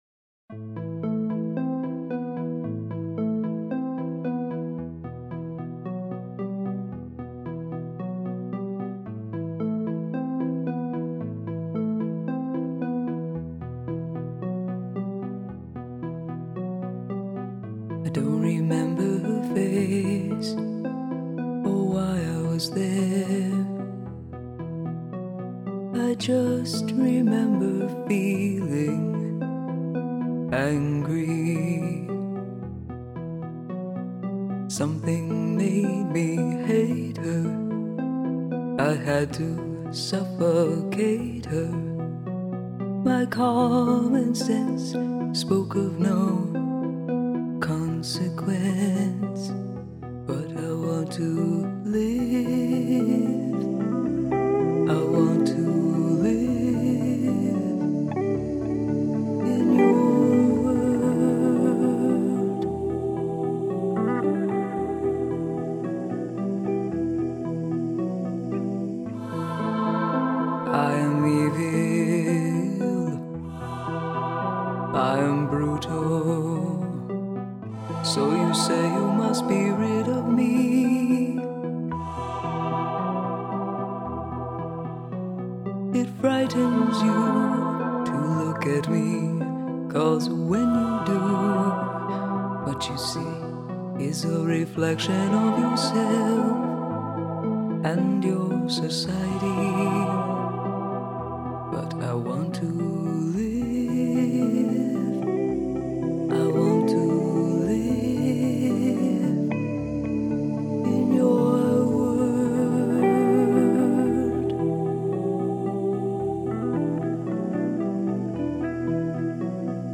creepy song from the point of view of a murderer